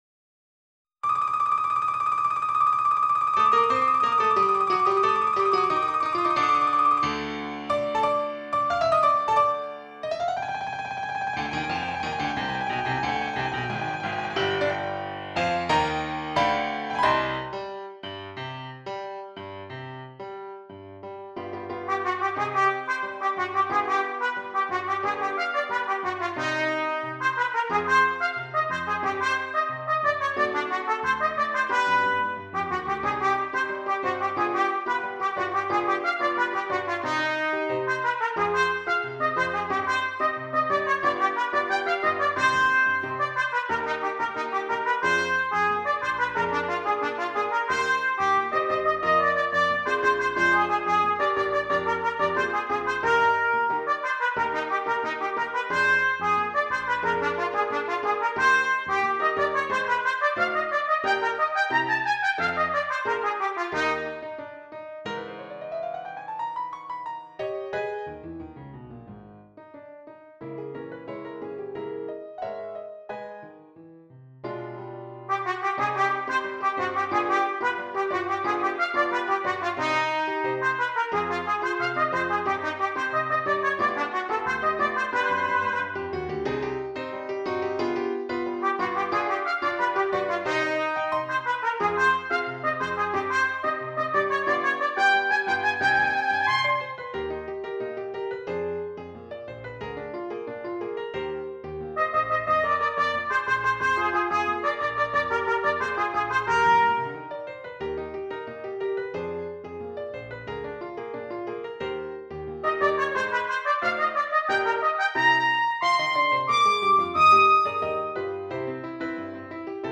Trumpet and Piano